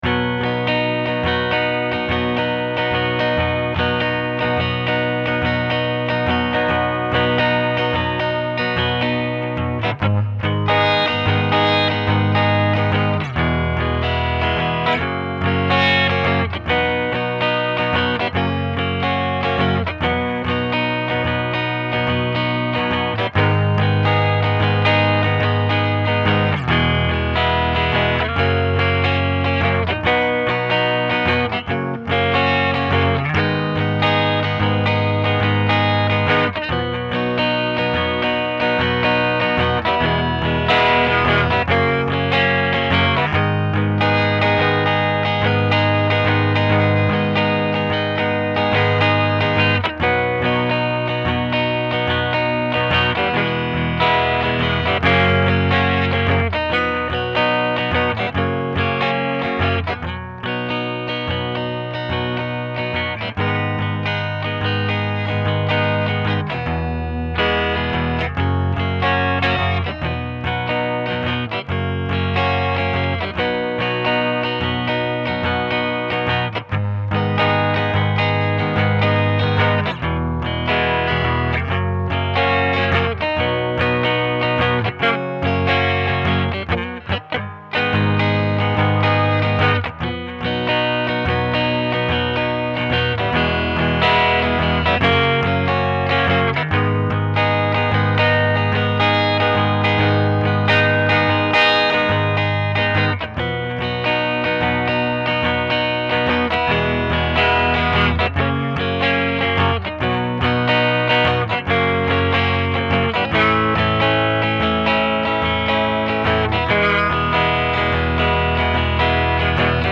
you may have asked me for a version of this song you can sing karaoke to.
I-Wanna-Wreck-My-Car-Karaoke.mp3